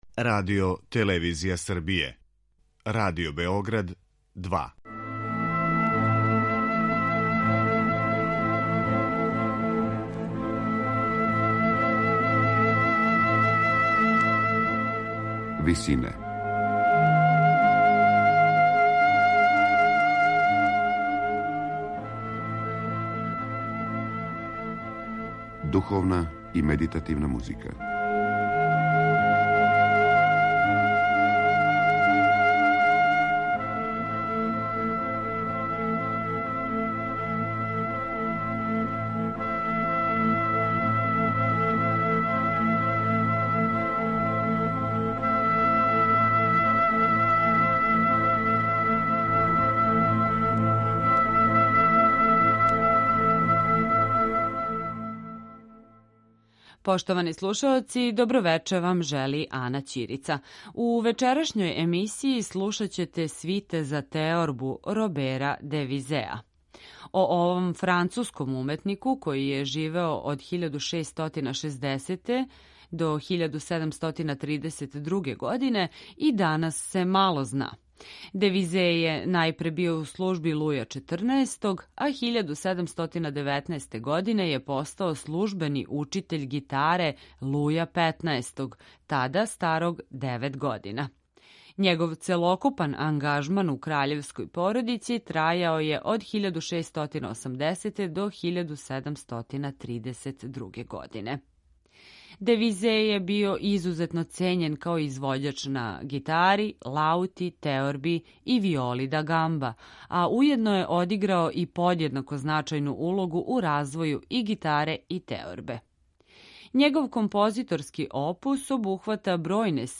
Комади за теорбу